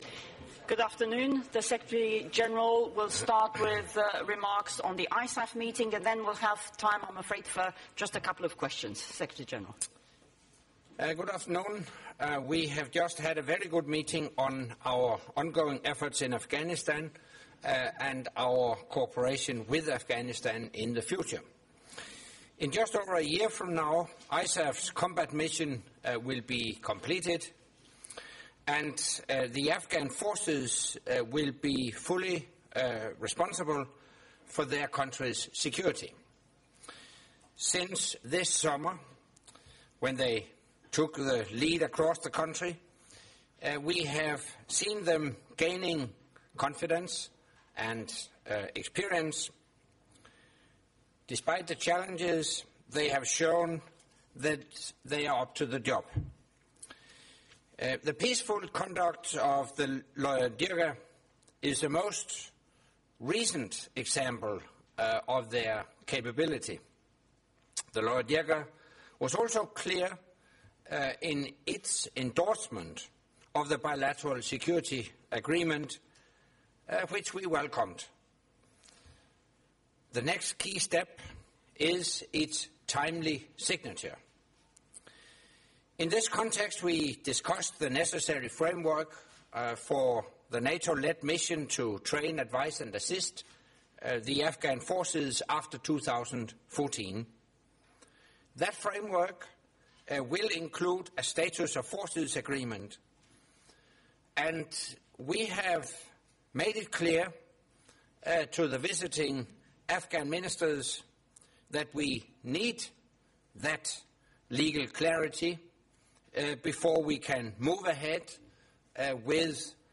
Press conference by NATO Secretary General Anders Fogh Rasmussen following the meeting of the North Atlantic Council with non-NATO ISAF contributing nations in Foreign Ministers session